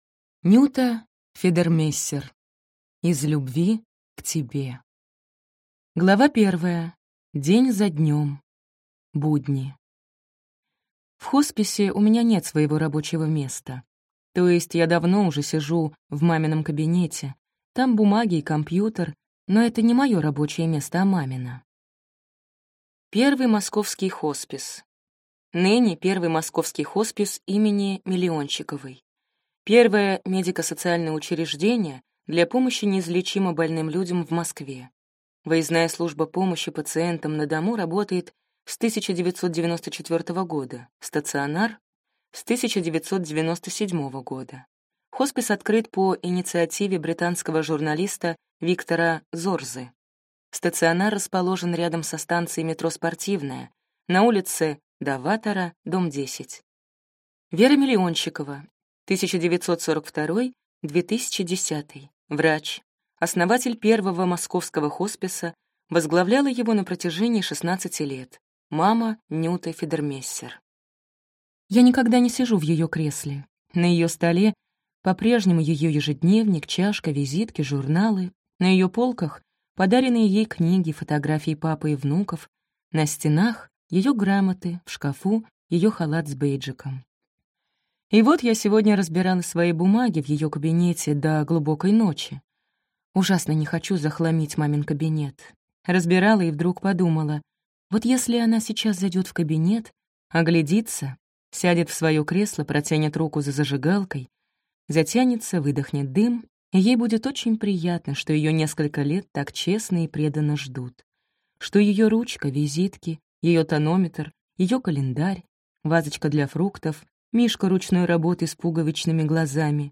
Аудиокнига Из любви к тебе | Библиотека аудиокниг
Прослушать и бесплатно скачать фрагмент аудиокниги